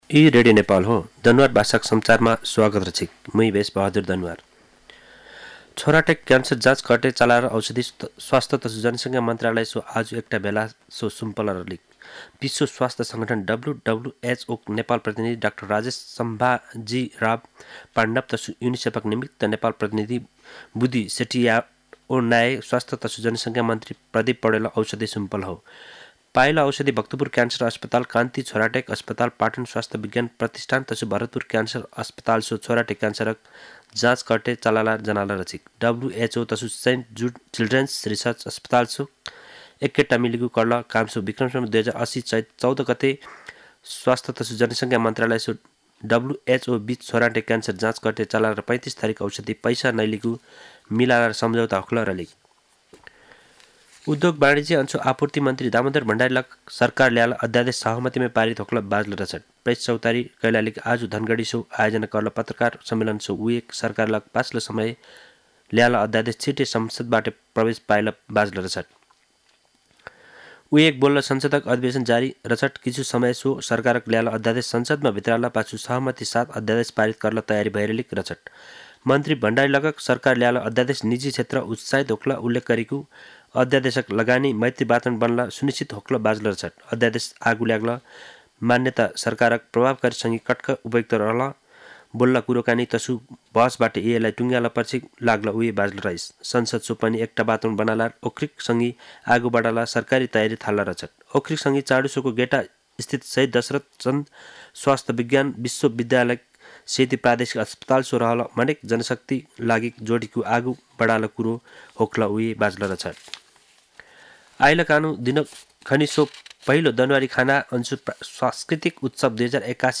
दनुवार भाषामा समाचार : ९ फागुन , २०८१
danuwar-news-4.mp3